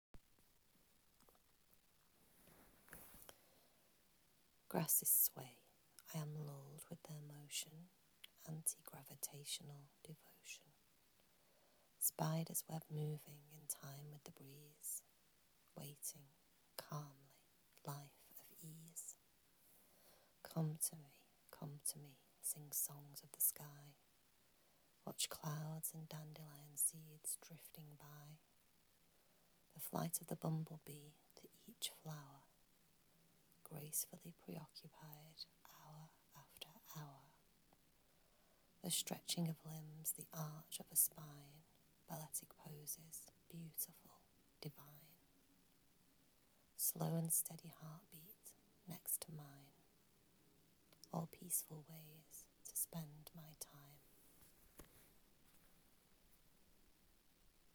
You read this very well...